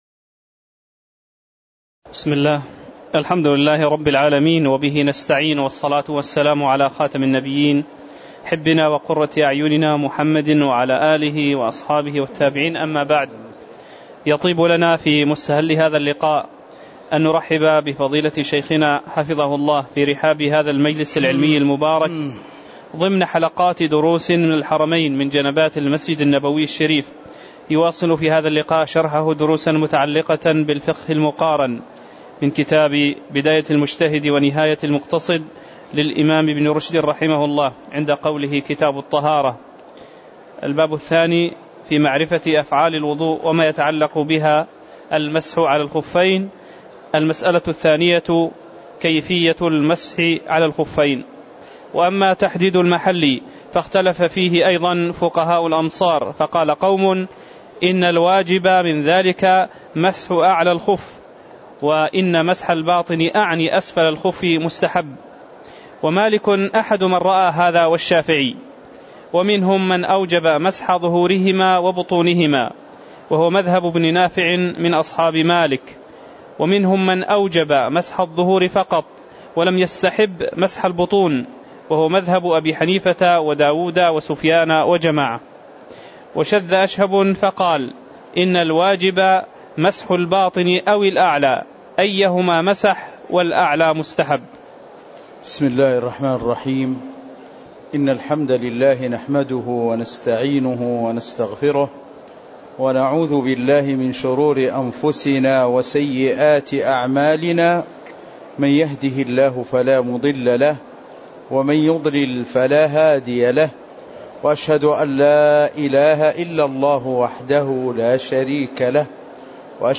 تاريخ النشر ٢٩ رجب ١٤٣٩ هـ المكان: المسجد النبوي الشيخ